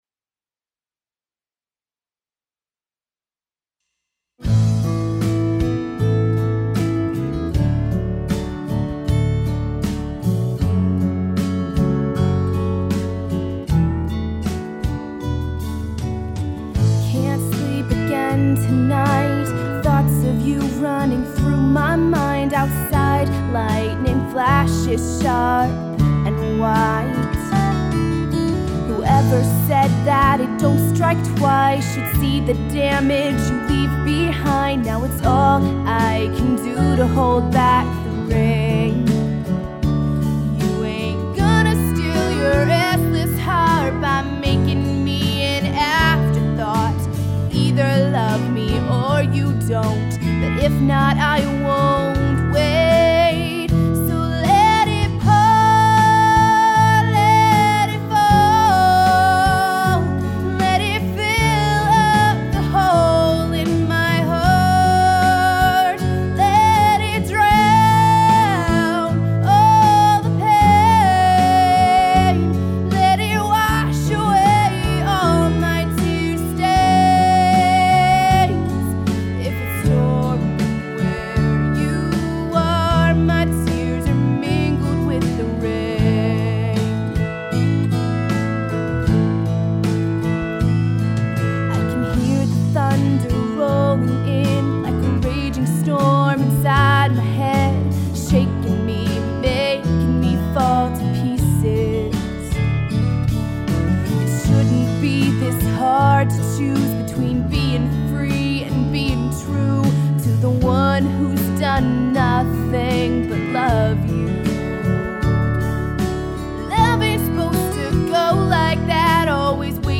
country singer-songwriter